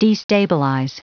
Prononciation du mot destabilize en anglais (fichier audio)
Prononciation du mot : destabilize